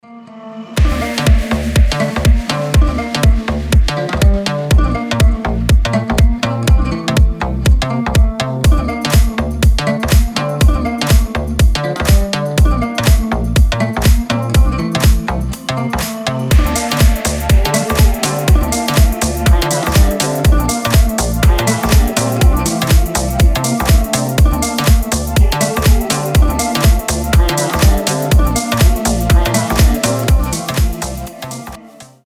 • Качество: 320, Stereo
гитара
ритмичные
deep house
без слов
красивая мелодия
восточные
Шикарные гитарные переливы